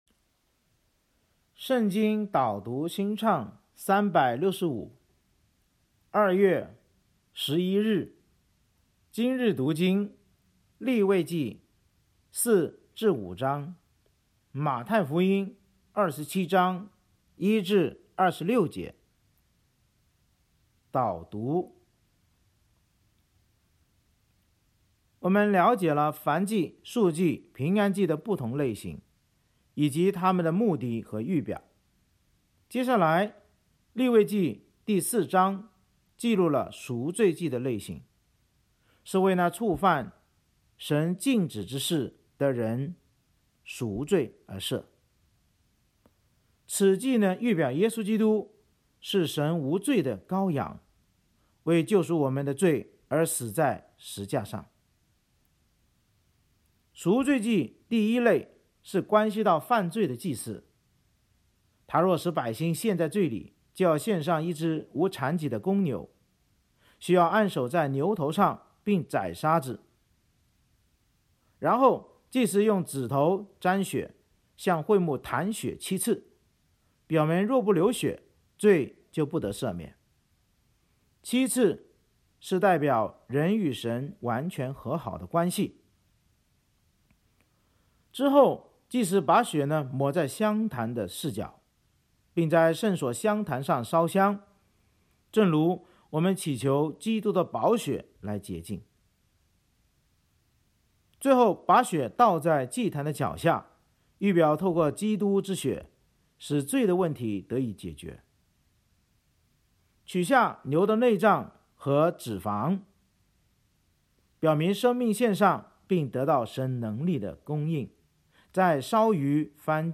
圣经导读&经文朗读 – 02月11日（音频+文字+新歌）